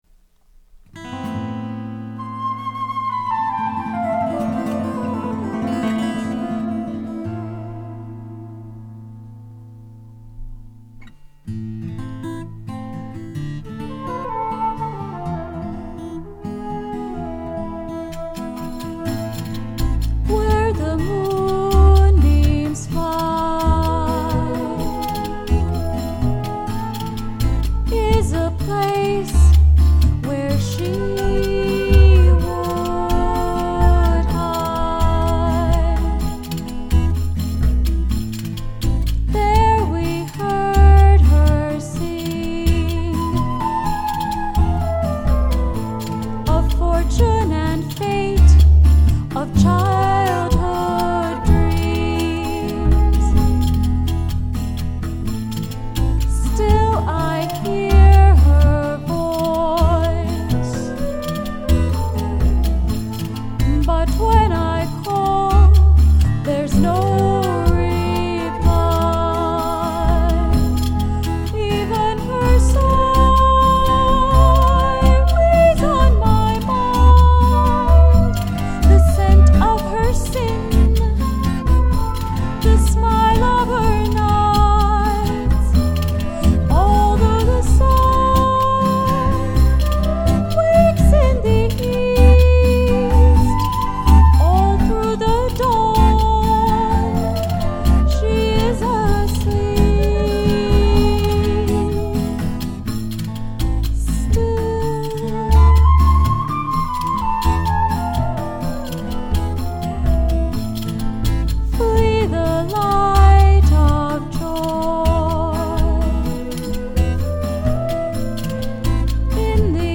ROUGH MIX